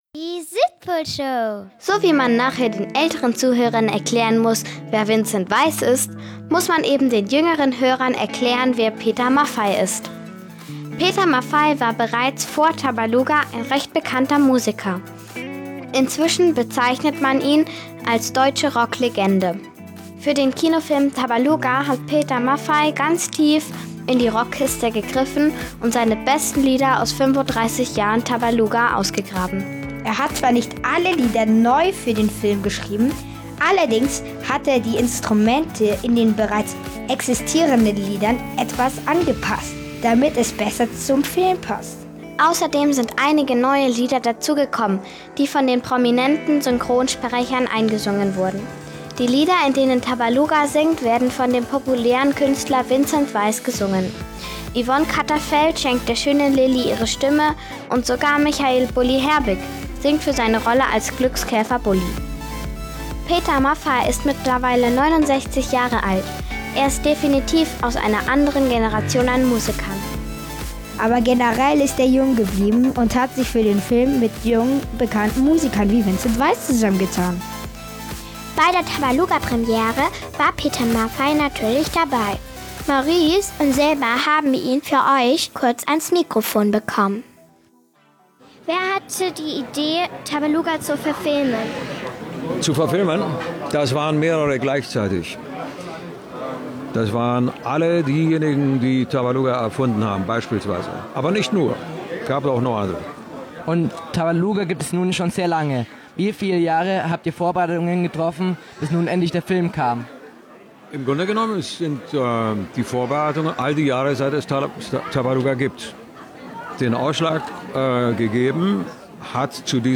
Interview mit Peter Maffay
"Tabaluga"-Papa am roten Teppich vor das Mikrofon bekommen.